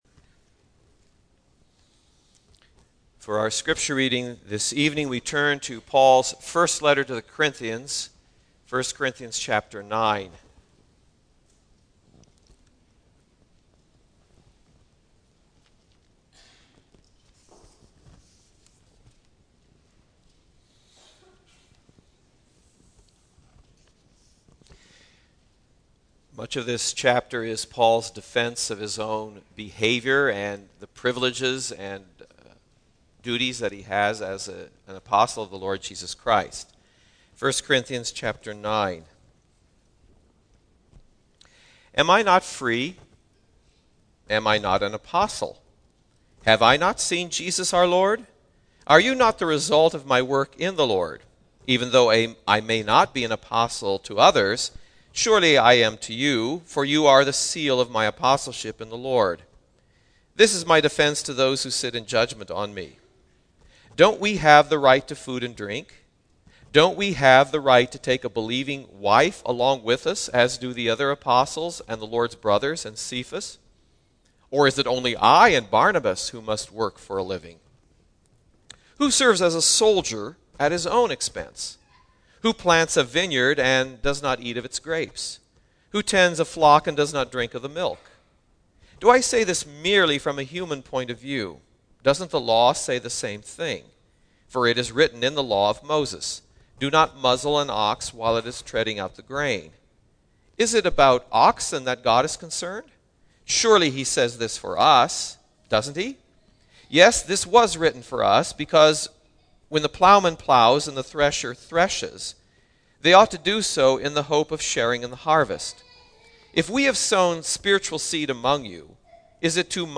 Single Sermons - Lynwood United Reformed Church - Page 28